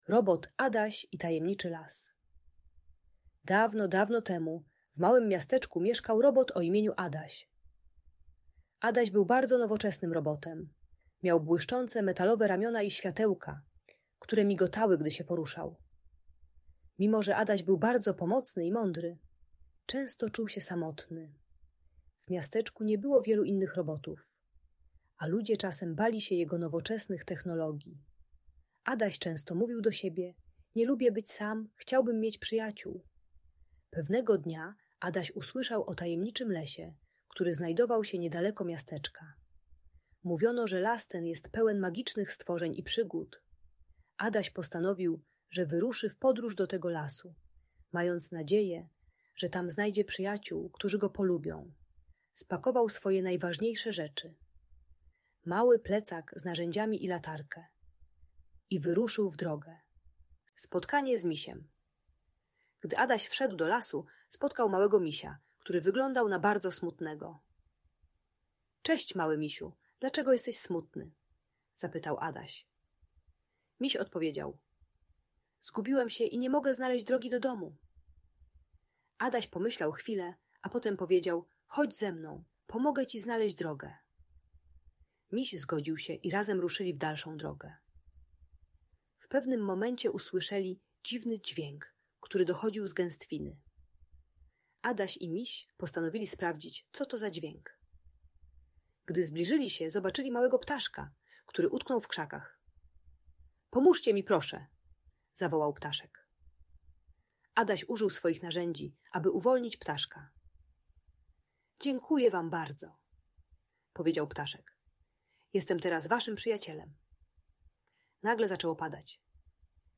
Robot Adaś i Tajemniczy Las - Bunt i wybuchy złości | Audiobajka